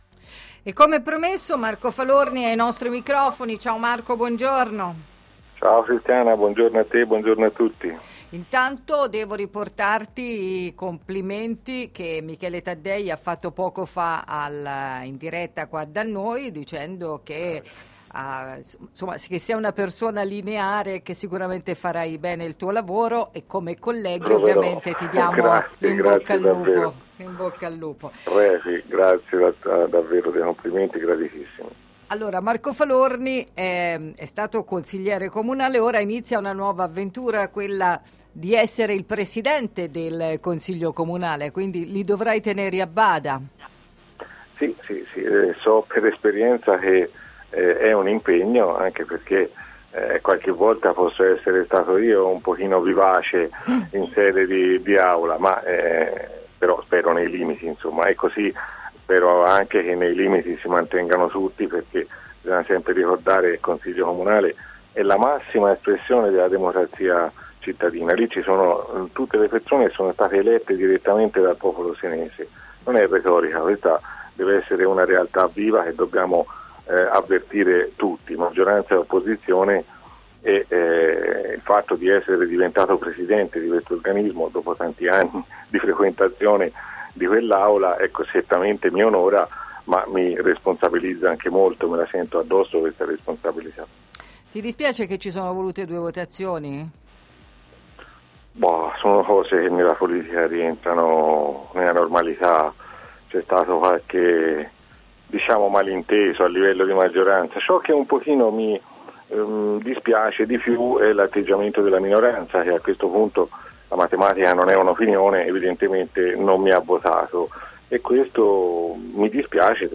Secondo il neo presidente del consiglio comunale Marco Falorni non c’è da scandalizzarsi, “sono cose che possono succedere in politica” ha detto stamani ad Antenna Radio Esse parlando del suo nuovo incarico e dei primi impegni istituzionali.